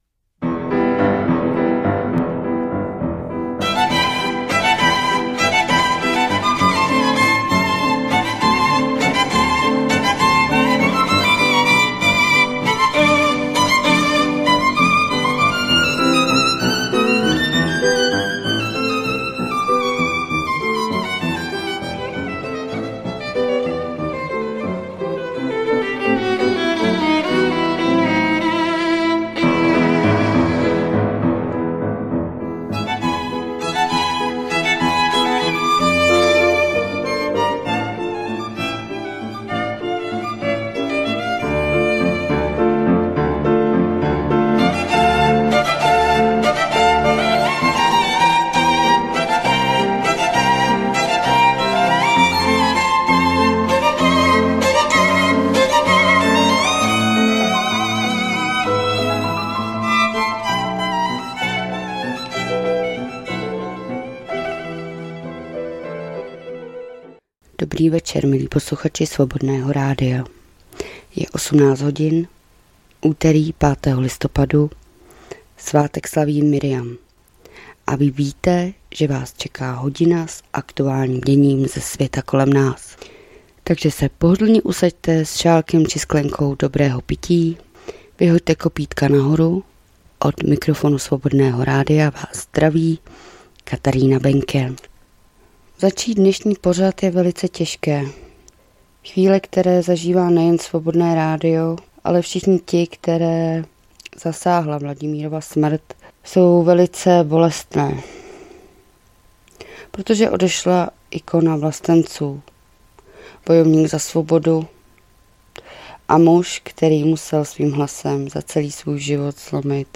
2024-11-05 - Zpravodajský přehled.